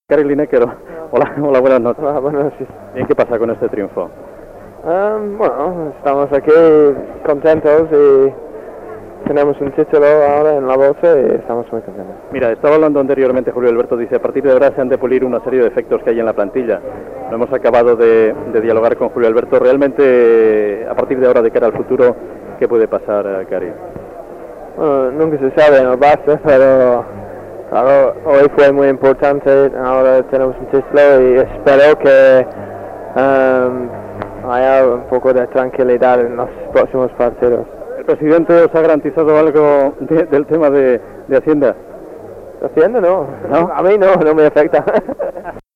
Entrevista al jugador Gary Lineker després que el F.C. Barcelona guanyés la copa del Rei de futbol masculí en vèncer a la Real Sociedad per 1 a 0 a l'estadi Santiago Bernabeu de Madrid
Esportiu